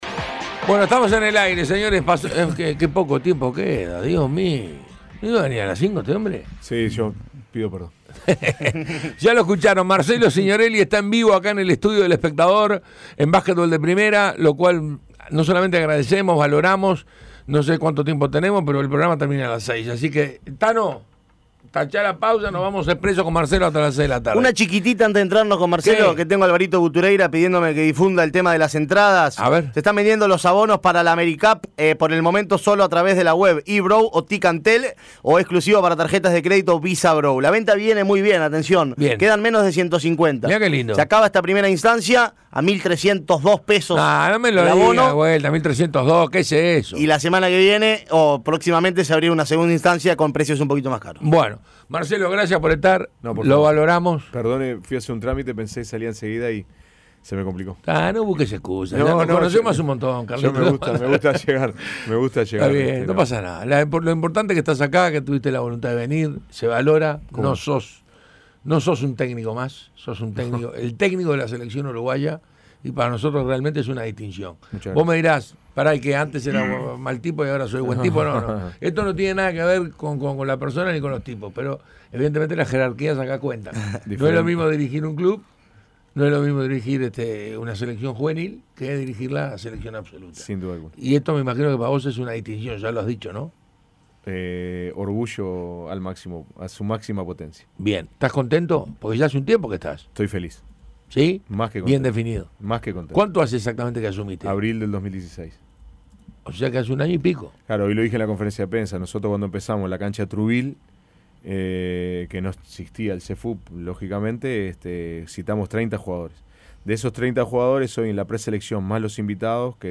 Entrevista completa
que visitó los estudios de El Espectador para charlar con el panel de Básquetbol de Primera de cara a la competencia internacional.